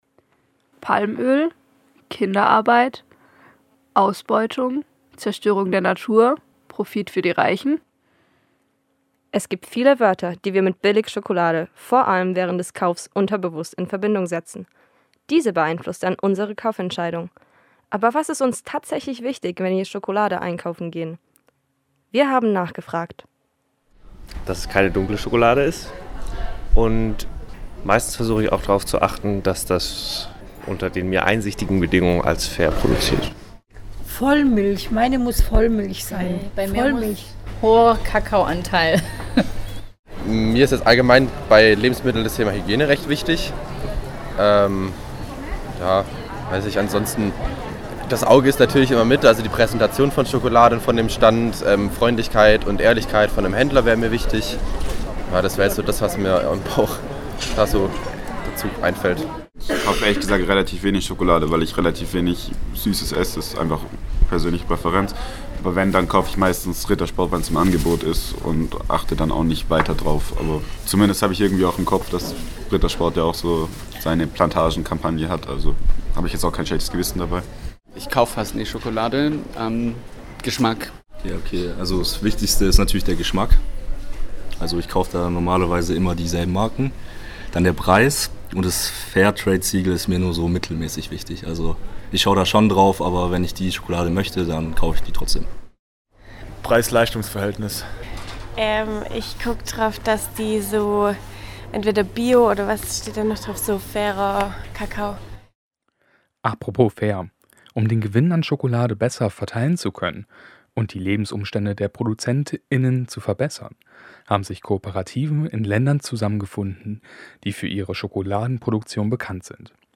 Sie waren für euch auf der ChocolArt in Tübingen und haben bei Passant_innen nachgefragt, welche Eigenschaften sie wichtig beim Kauf von Schokolade finden. Bei der Umfrage kam heraus, dass nicht nur der Preis, sondern auch der Geschmack, die Art der Schokolade und Fairness eine wichtige Rolle in der Kaufentscheidung spielen.
Umfrage Schokolade